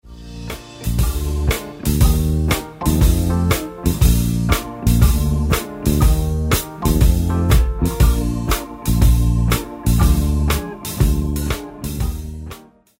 12 Bar Gospel/Soul Blues.